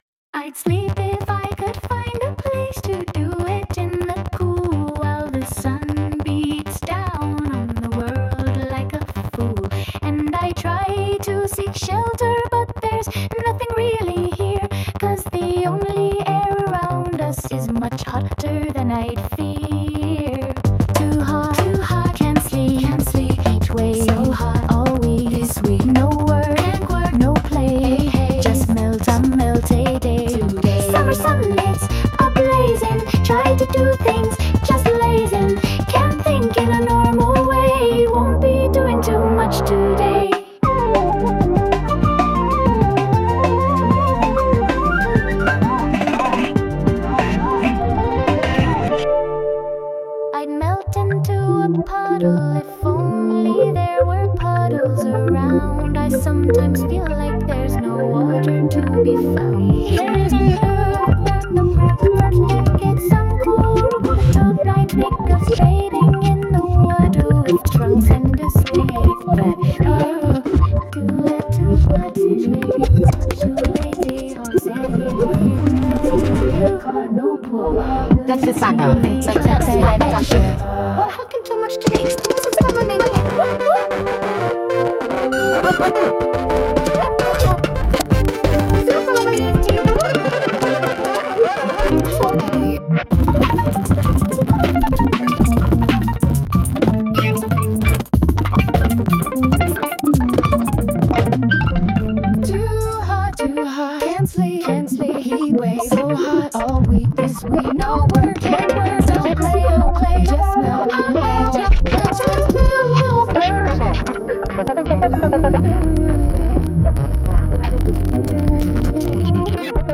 Sung by Suno